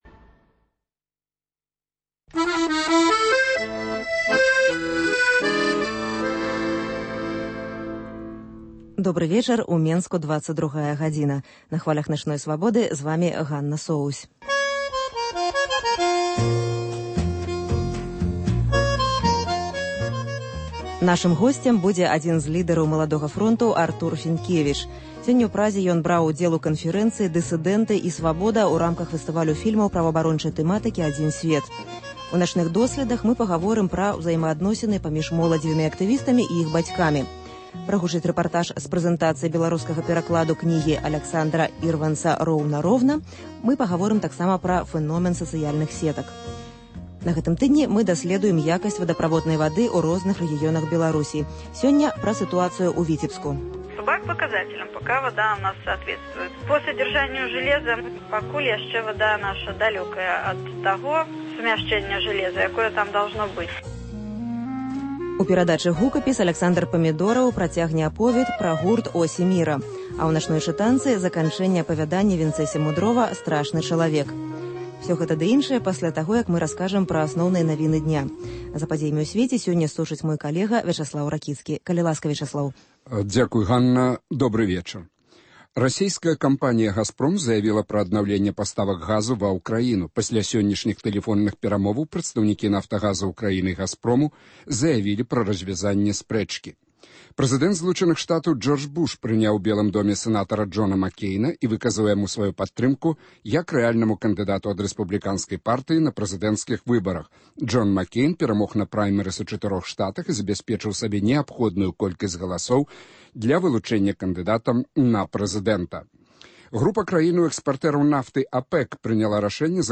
* “Начныя досьледы” - пра ўзаемаадносіны паміж моладзевымі актывістамі і іх бацькамі. * Рэпартаж з прэзэнтацыі беларускага перакладу кнігі Аляксандра Ірванца "Ровно-Рівне".